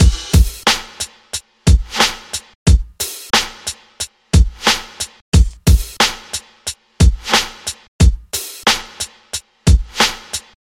老学校鼓 90 bpm
描述：嘻哈鼓 90 BPM。
Tag: 90 bpm Hip Hop Loops Drum Loops 1.79 MB wav Key : Unknown